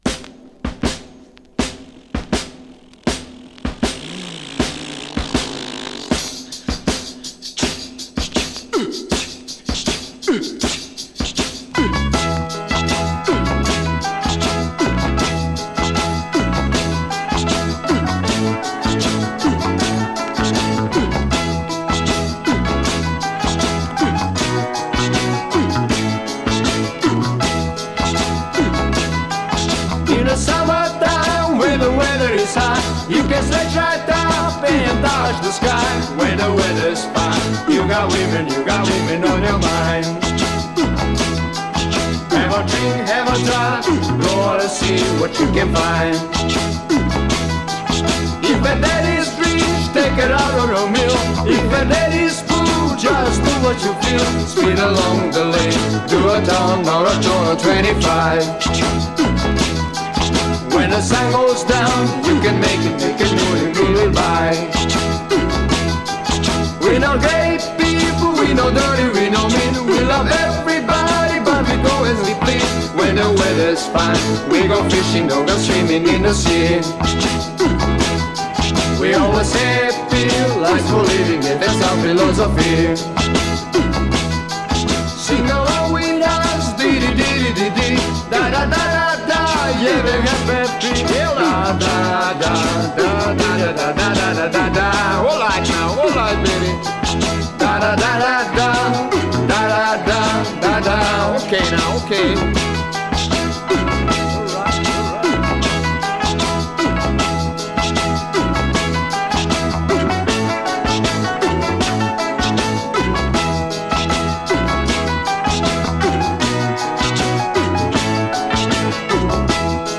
Vocals, Piano, Hammond B3
Vocals, Bass
Drums, Percussion
Vocals, Sacophon, Flute
Vocals, Guitars